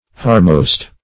Search Result for " harmost" : The Collaborative International Dictionary of English v.0.48: Harmost \Har"most\ (h[aum]r"m[o^]st), n. [Gr.